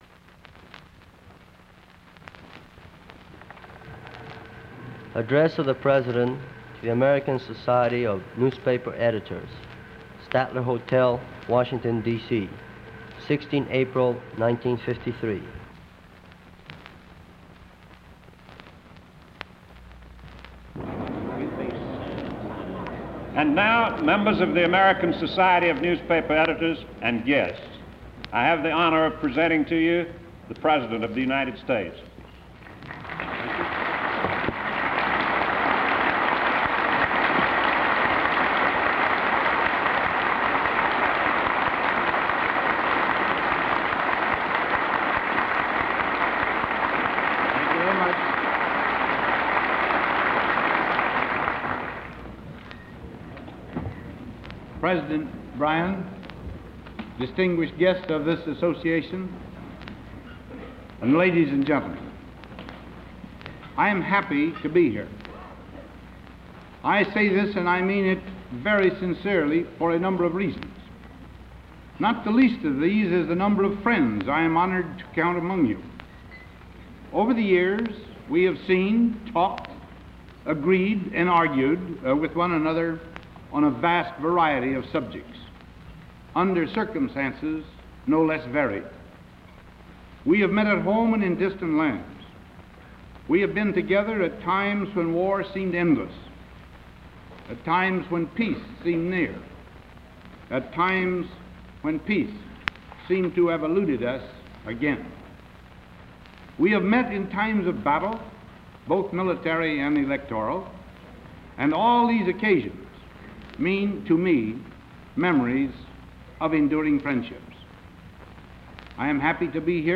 Chance for Peace Eisenhower gives this speech before the American Society for Newspaper Editors, shortly after the death of Joseph Stalin.